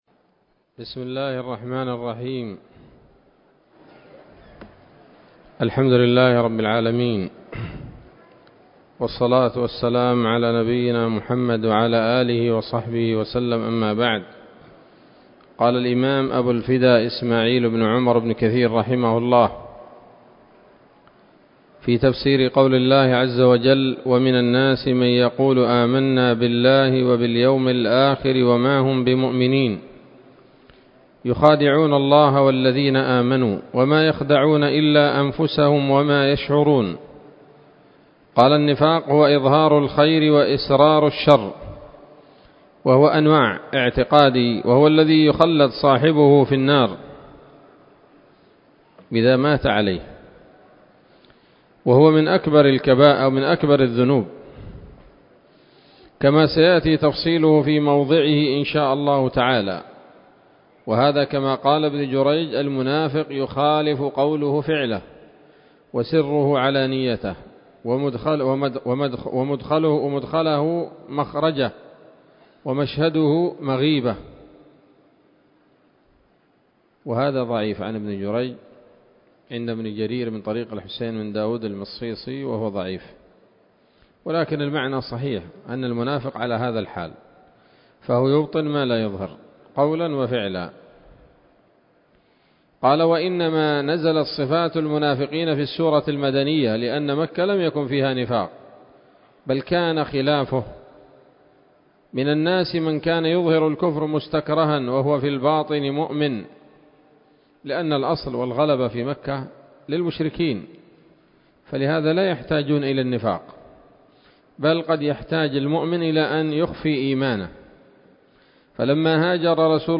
الدرس الرابع عشر من سورة البقرة من تفسير ابن كثير رحمه الله تعالى